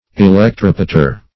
Search Result for " electrepeter" : The Collaborative International Dictionary of English v.0.48: Electrepeter \E`lec*trep"e*ter\ ([-e]`l[e^]k*tr[e^]p"[-e]*t[~e]r), n. [Electro + Gr. tre`pein to turn.] An instrument used to change the direction of electric currents; a commutator.